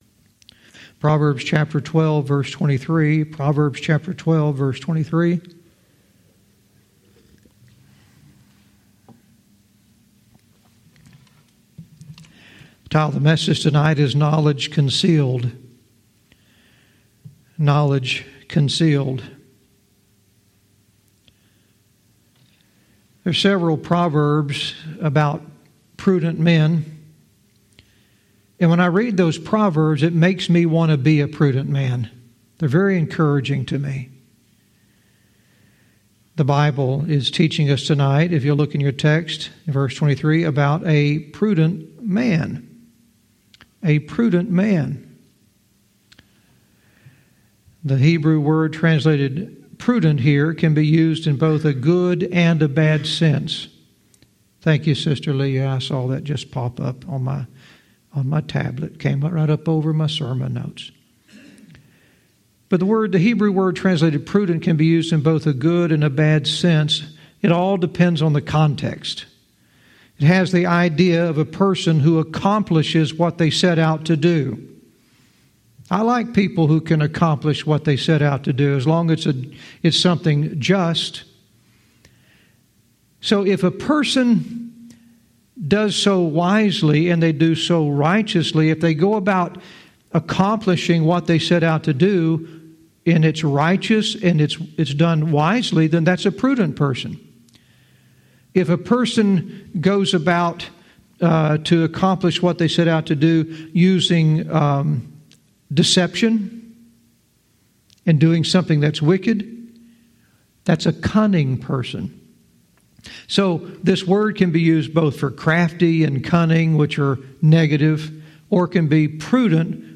Verse by verse teaching - Proverbs 12:23 "Knowledge Concealed"